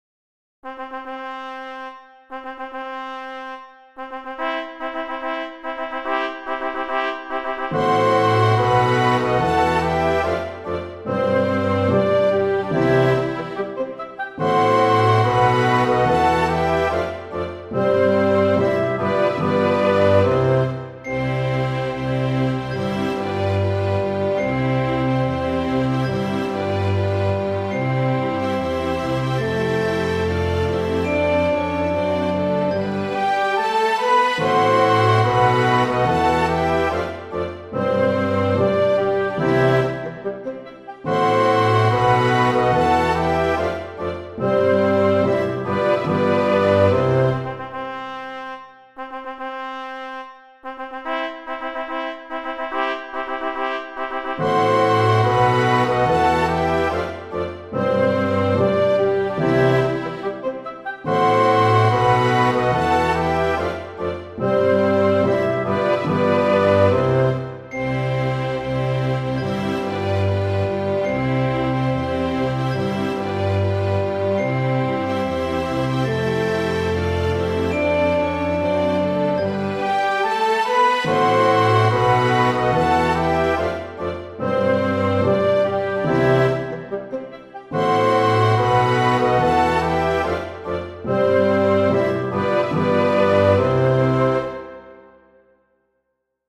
Lo proponiamo in versione didattica per flauto.